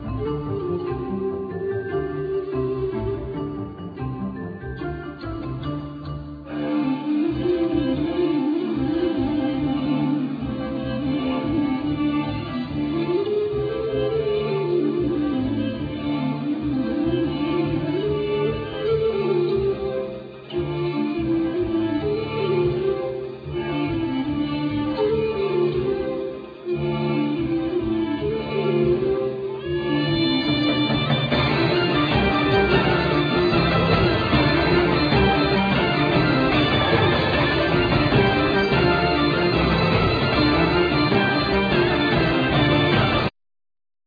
Gaida
Bass Clarinet,Soprano Saxophone
Flute
Arpa,Zanfonia
Viola da Gamba,Violin
MIDI instruments
Vocal
Tambor chamanico,zarb,Vocal
Viola d'amore con clavijas,Violin debolsillo,Vocal